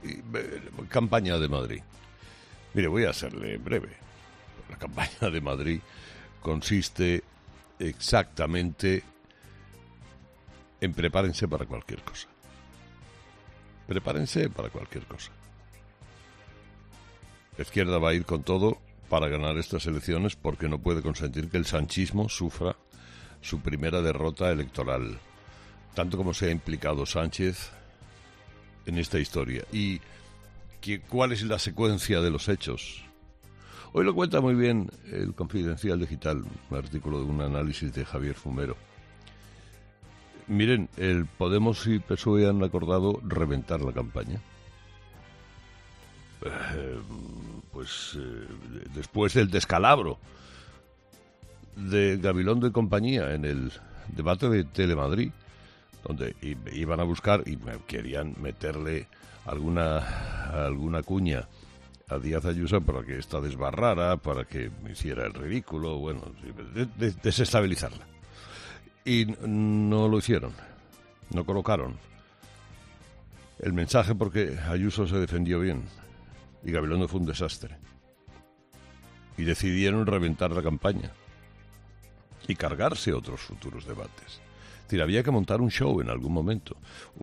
El presentador de 'Herrera en COPE' ha analizado la nueva estrategia que van a adoptar PSOE y Unidas Podemos para esta semana, antes de que se celebren las elecciones